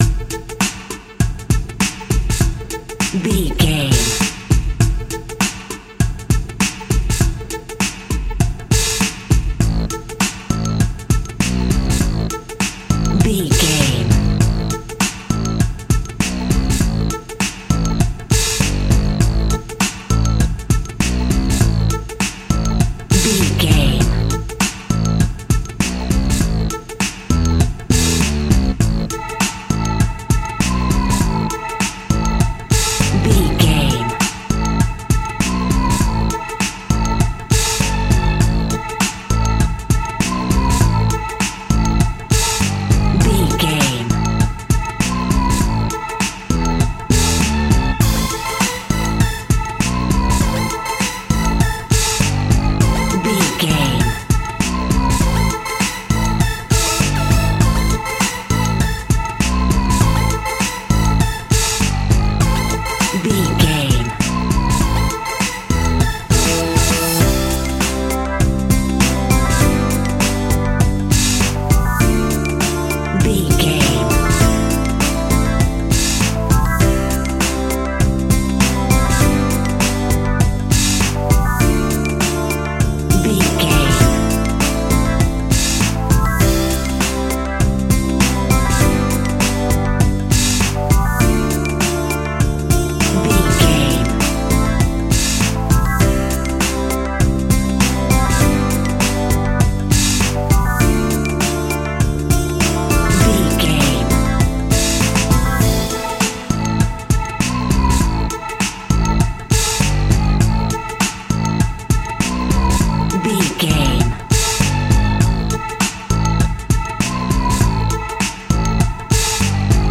Gangster Zombie Hip Hop.
Aeolian/Minor
hip hop
chilled
laid back
groove
hip hop drums
hip hop synths
piano
hip hop pads